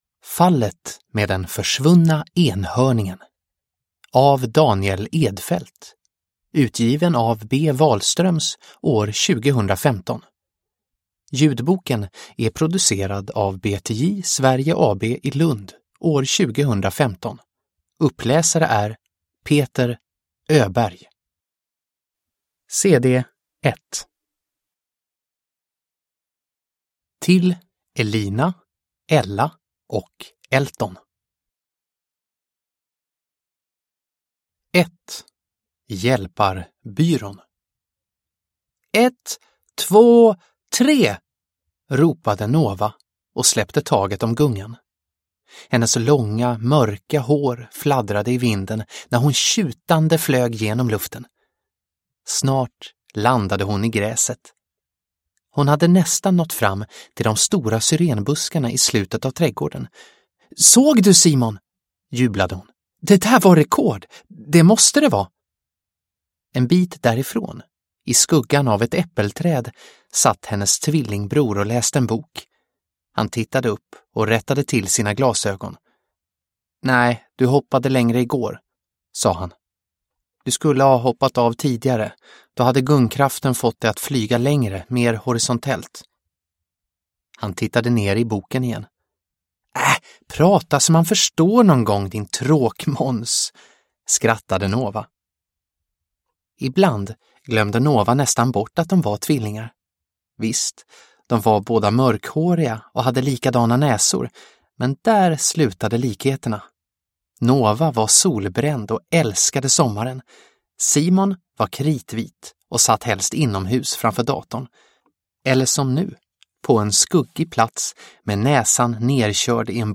Hjälparbyrån. Fallet med den försvunna enhörningen – Ljudbok – Laddas ner